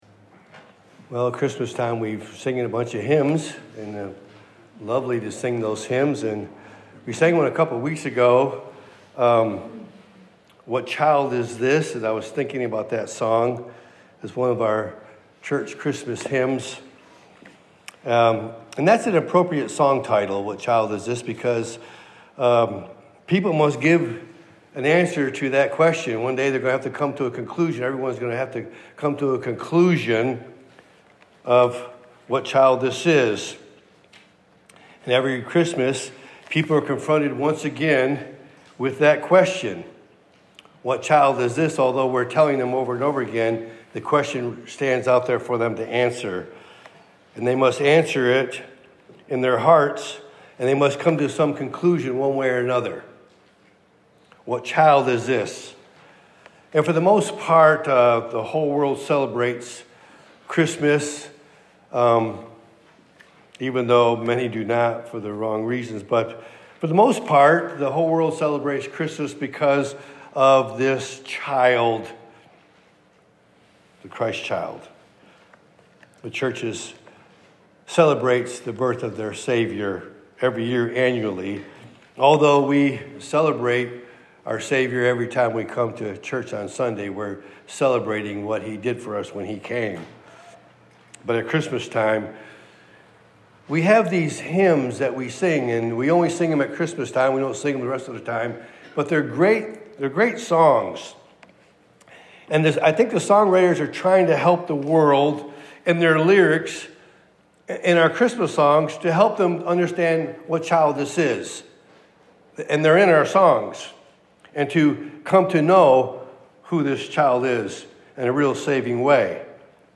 Topic: Sunday Morning